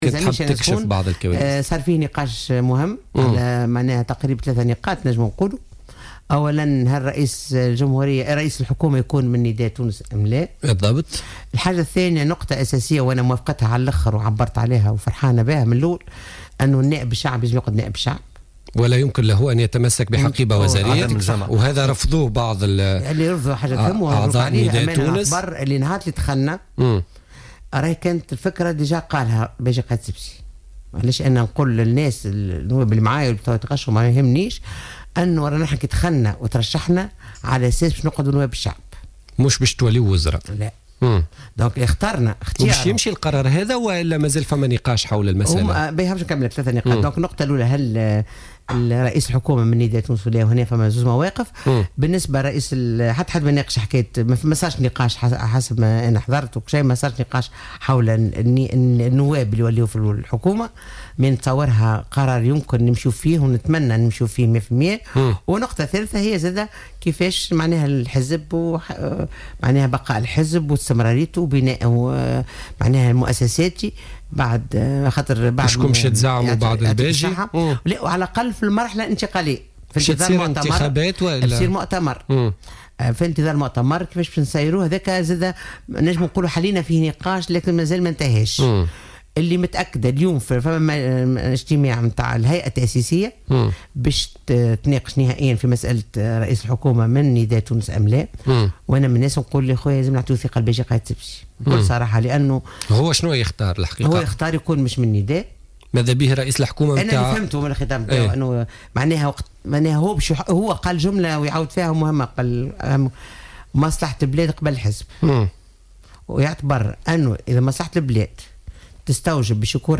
قالت النائب بمجلس الشعب عن حركة نداء تونس،بشرى بالحاج حميدة ضيفة برنامج "بوليتيكا" اليوم إن الباجي قائد السبسي يدعم فكرة تعيين رئيس للحكومة الجديدة من خارج حزب النداء.